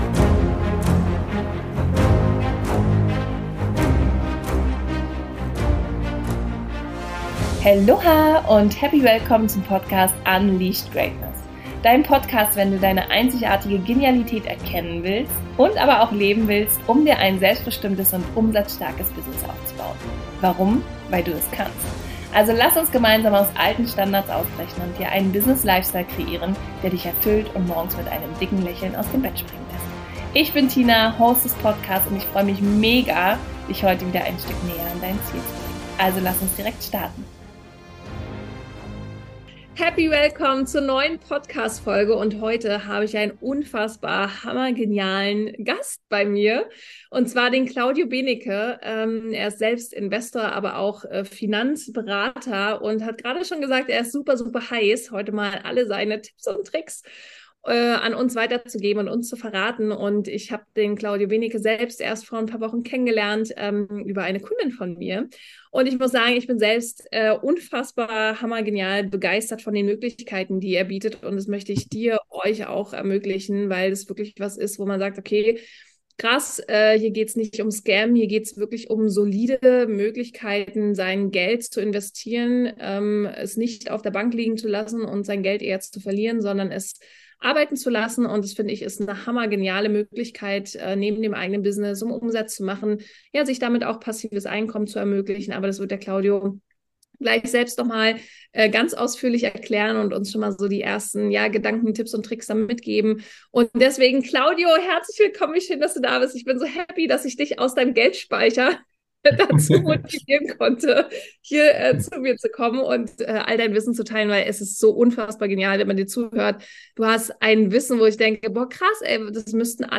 #018 // Smart investieren und finanzielle Freiheit gewinnen // Interview